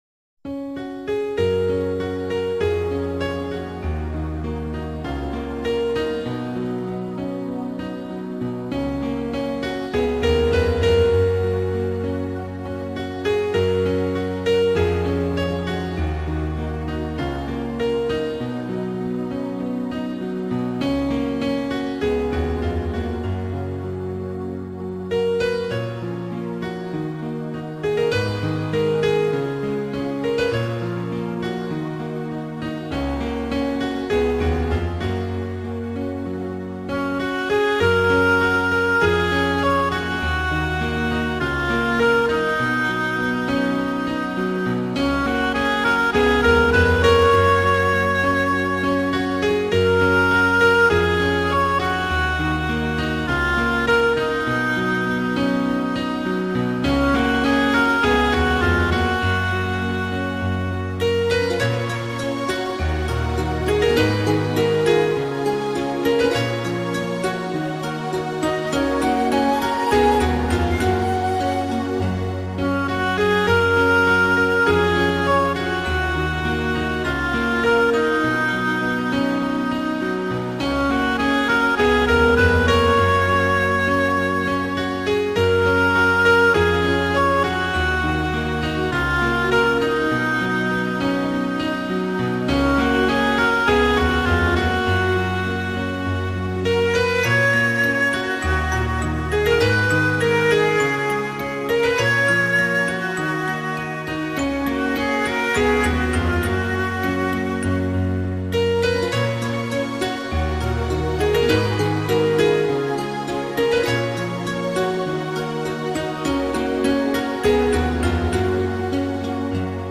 • 【素材】纯音乐